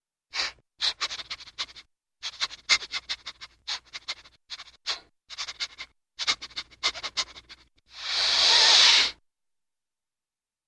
Звук зайца, вынюхивающего еду